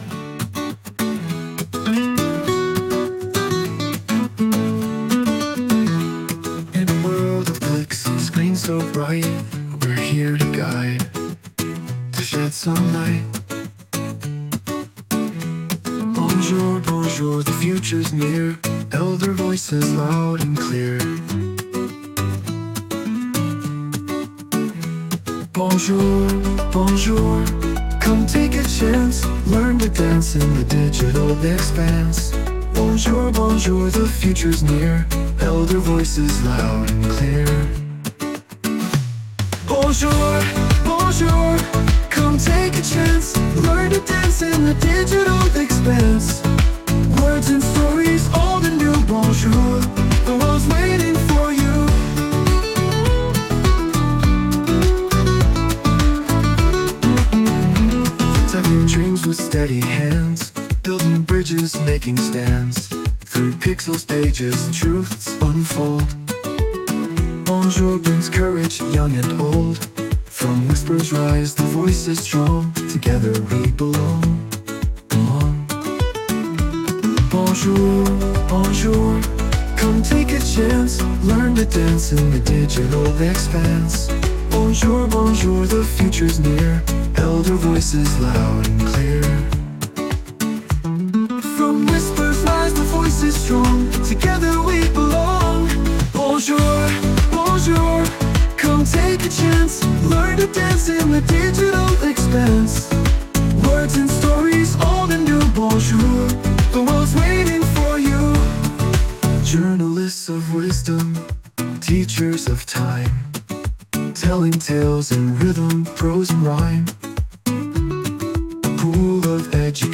„Freundliches Lied über das Erasmus+-Projekt namens Bonjour.